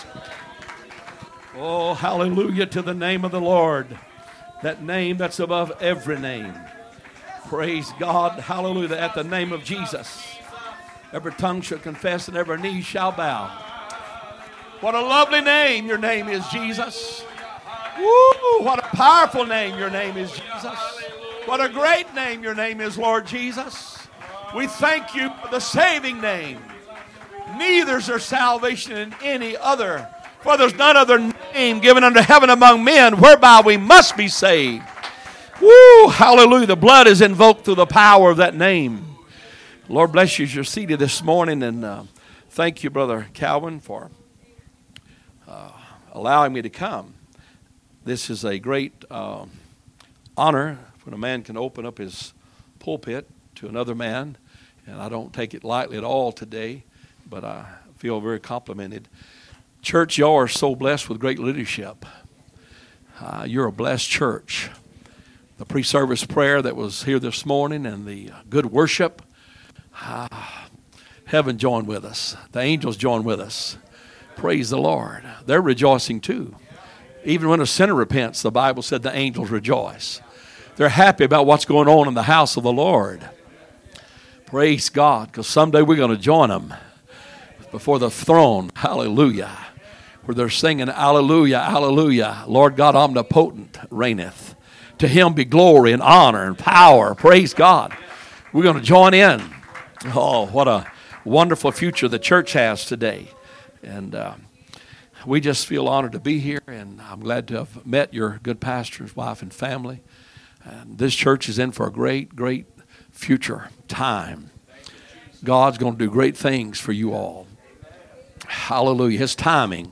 Sunday Morning Message
2025 Sermons